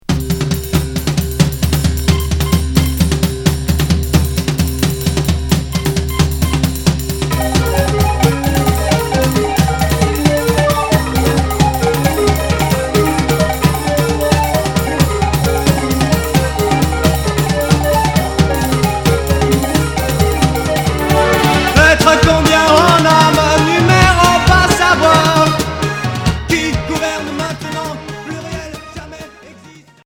New-wave expérimental Unique 45t retour à l'accueil